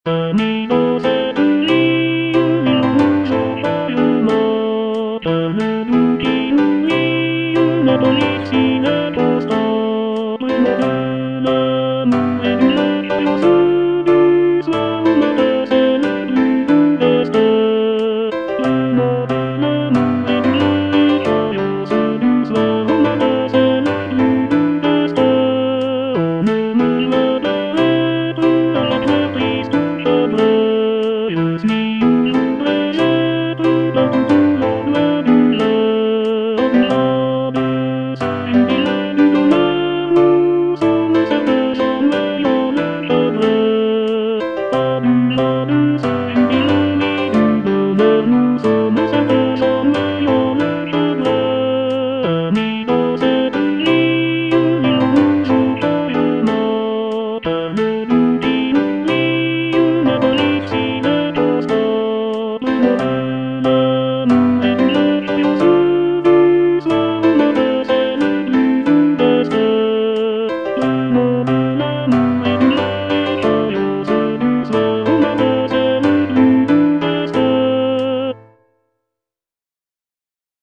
ANONYMOUS (ARR. R. DEMIEVILLE) - AMI, DANS CETTE VIE Tenor (Voice with metronome) Ads stop: auto-stop
"Ami, dans cette vie" is a traditional French folk song arranged by R. Demieville. The piece features a beautiful melody accompanied by gentle harmonies, creating a soothing and heartfelt atmosphere.